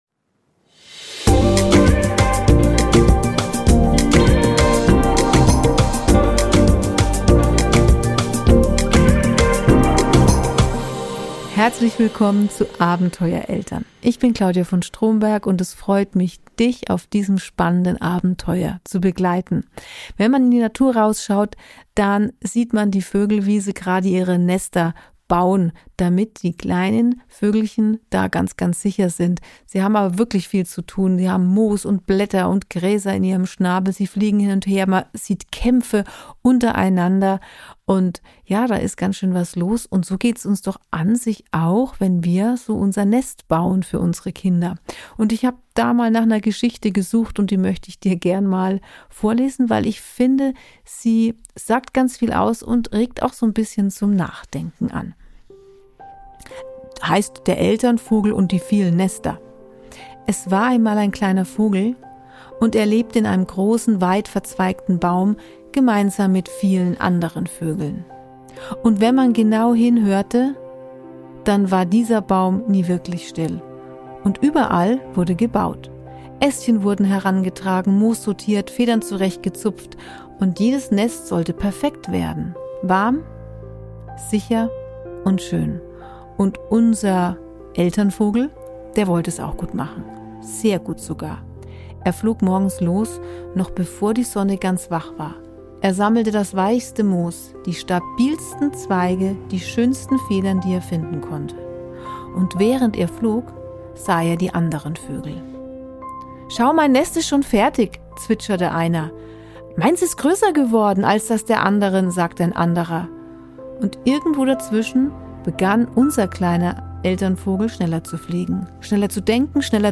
Eine kleine, ruhige Geschichte zum Durchatmen – für alle, die im